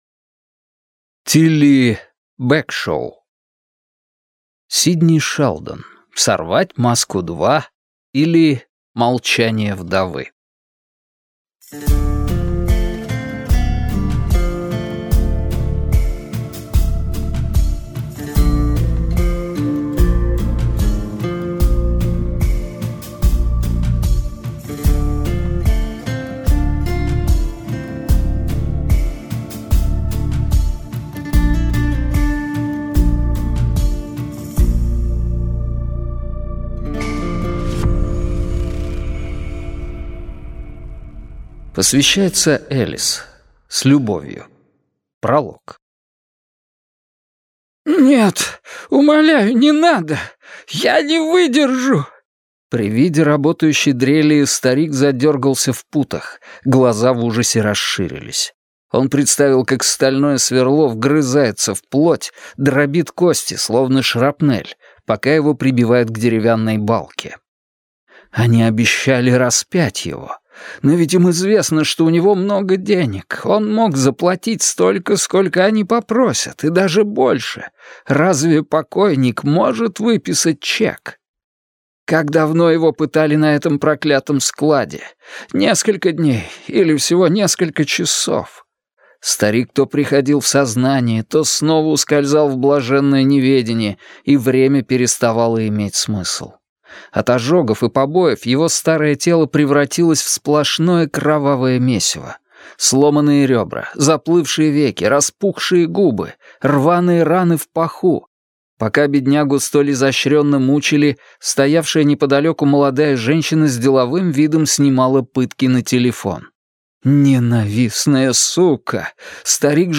Аудиокнига Сидни Шелдон. Сорвать маску-2, или Молчание вдовы | Библиотека аудиокниг